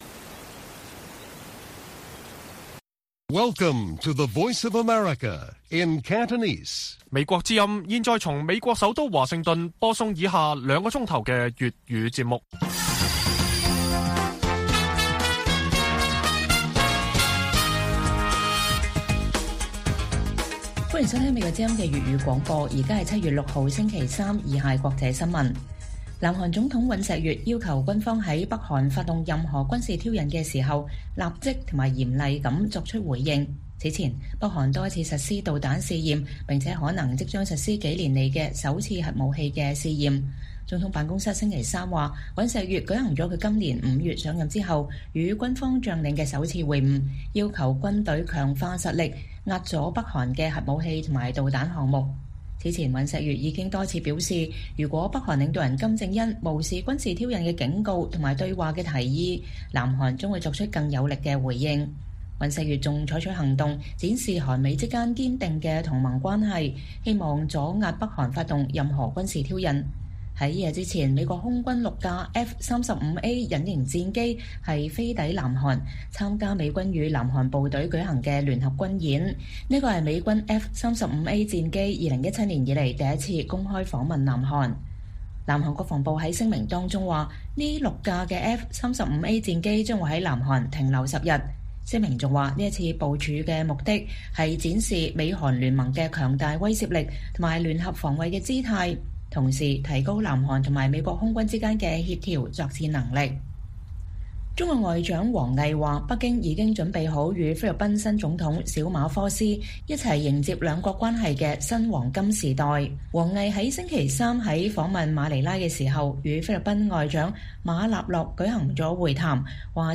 粵語新聞 晚上9-10點: 雙重打擊下澳門博彩業面臨轉型 業內人士憂步入死胡同